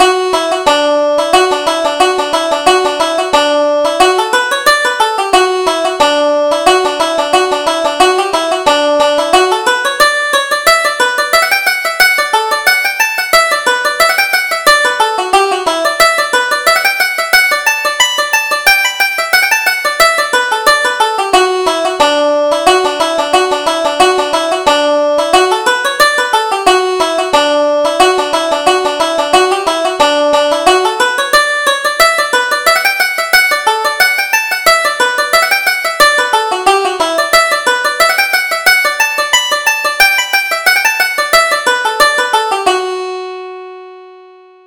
Reel: The Tinker's Reel